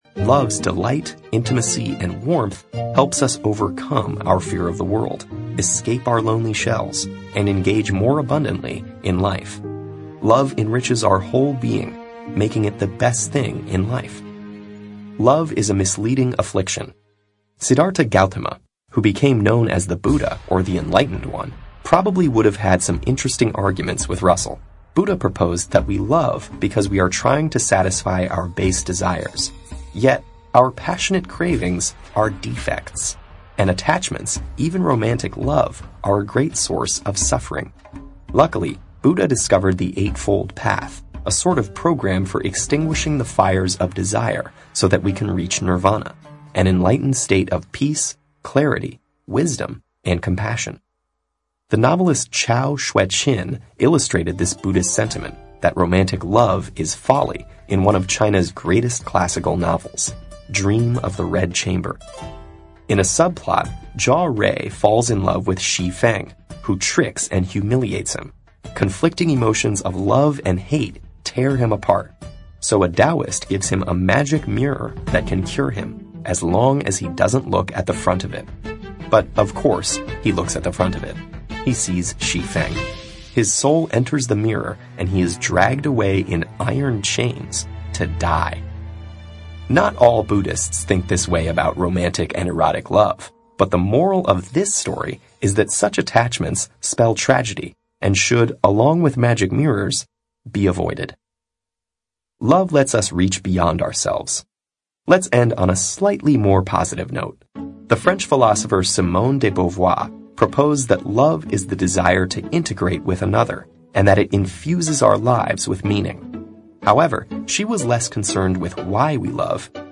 TED演讲:人类为何要相爱-一个哲学探讨(2) 听力文件下载—在线英语听力室